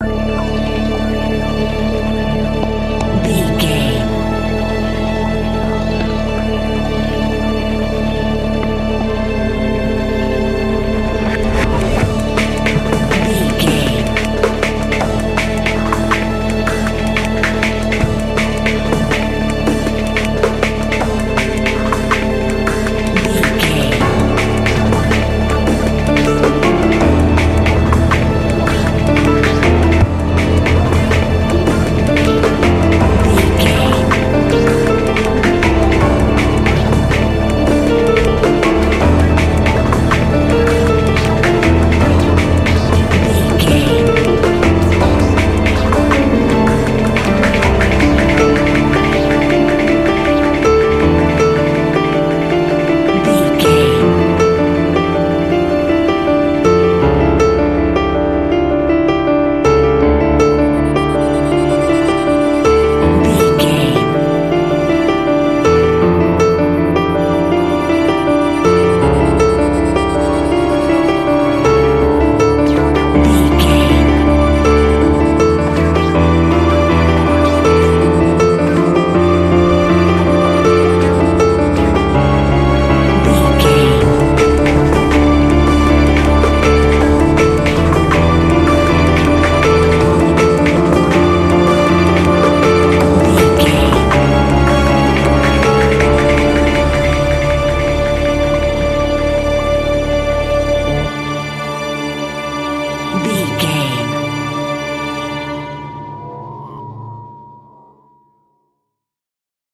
Thriller Action.
In-crescendo
Aeolian/Minor
Fast
tension
ominous
dark
suspense
eerie
strings
drums
synthesiser
piano
ambience
pads